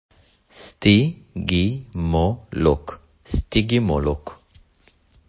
סטי-גי-מו-לוק